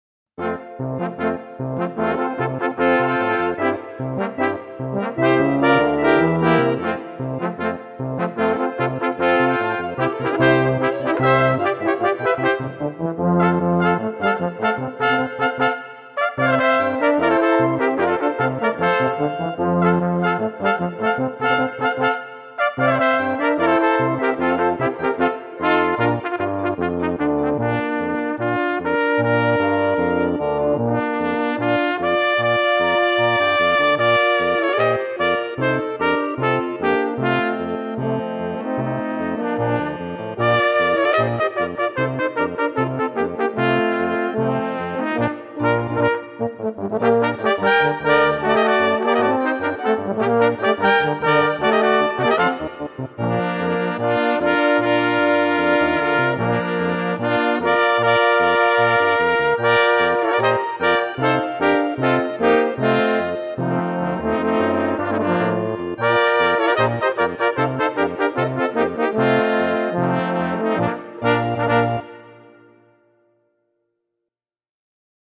Categorie Ensembles
Subcategorie Koperblaasinstrumenten met begeleiding
Bezetting Tanzlmusi